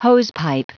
Prononciation du mot hosepipe en anglais (fichier audio)
Prononciation du mot : hosepipe
hosepipe.wav